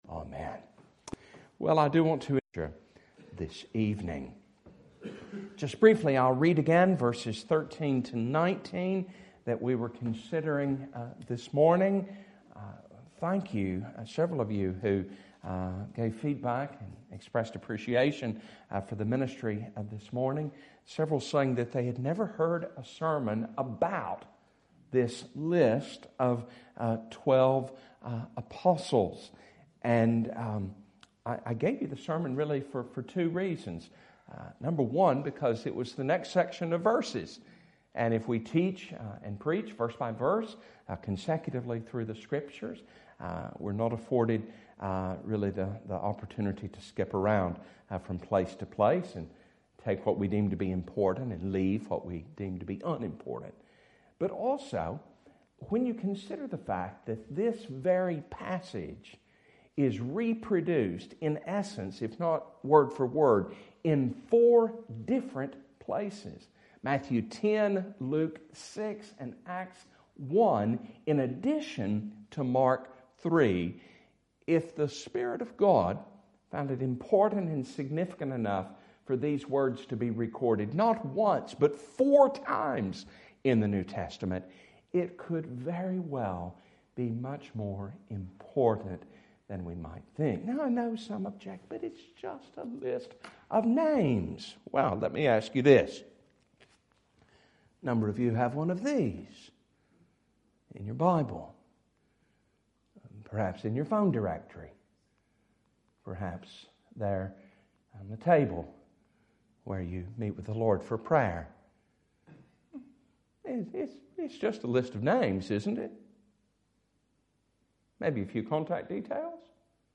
Passage: Mark 3:20-27 Service Type: Sunday Afternoon